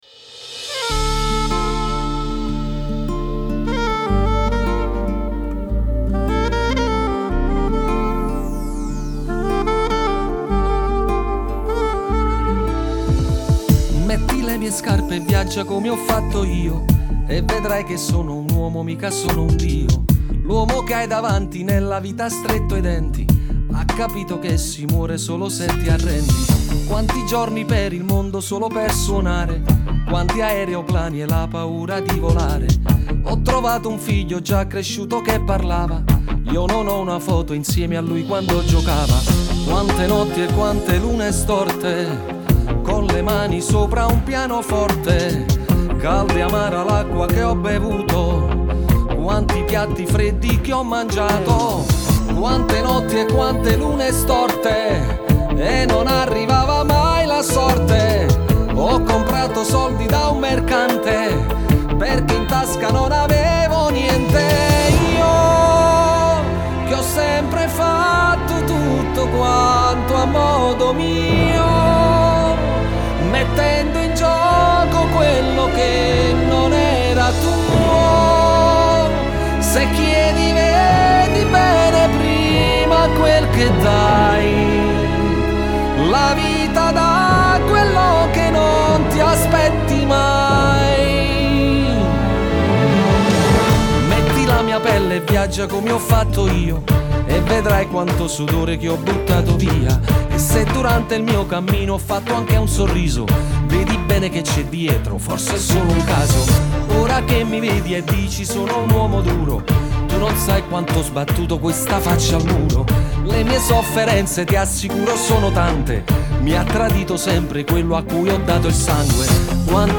Genre: Pop